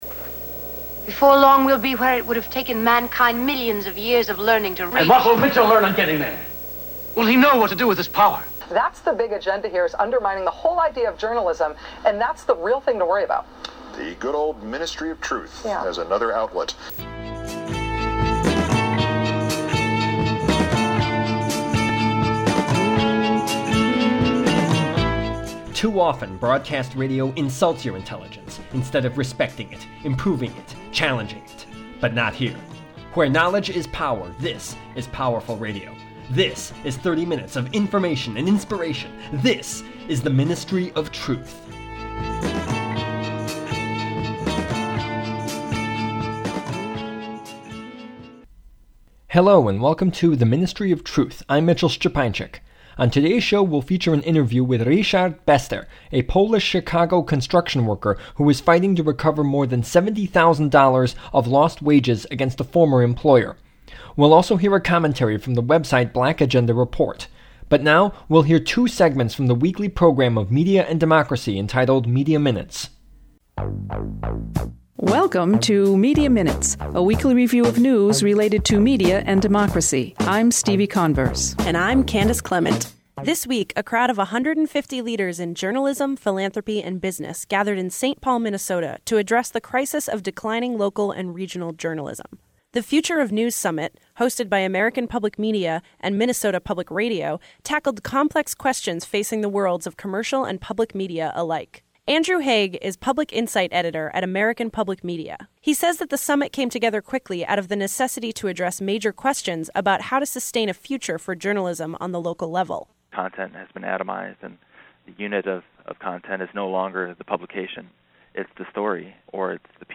The Ministry of Truth: Interview